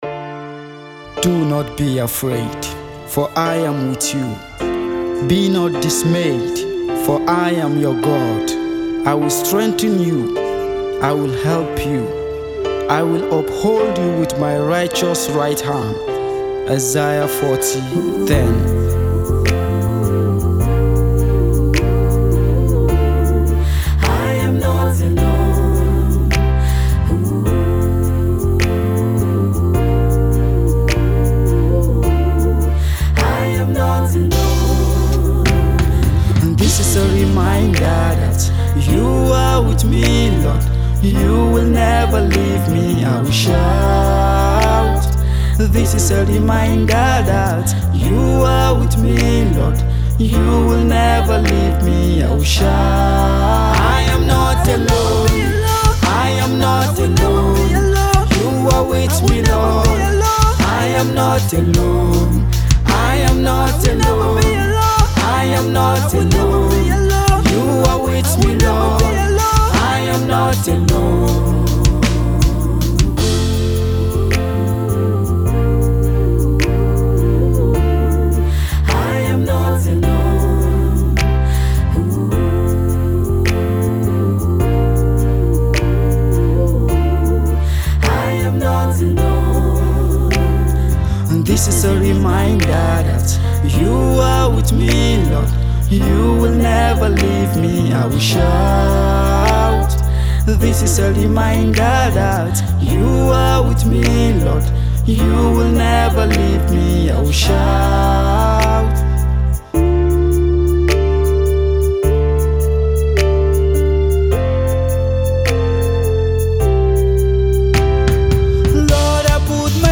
Nigerian gospel music
With soul-stirring lyrics and a captivating melody